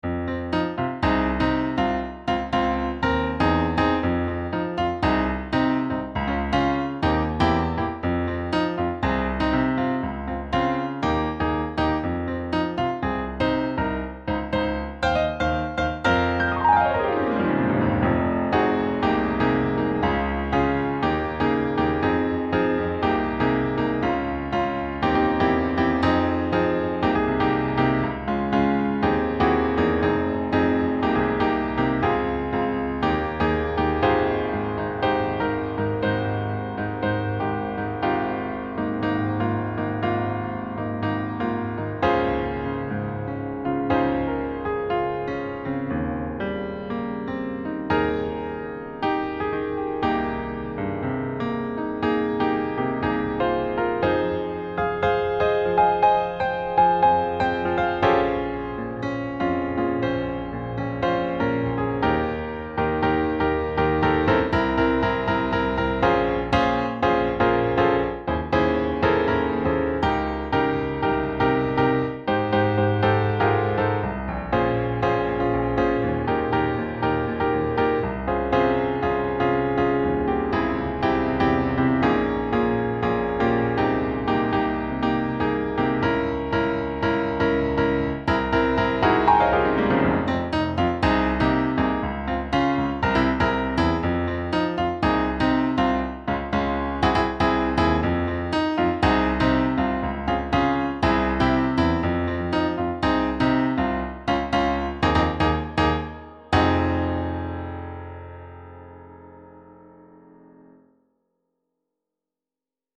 未分類 かっこいい ピアノ 疾走感 音楽日記 よかったらシェアしてね！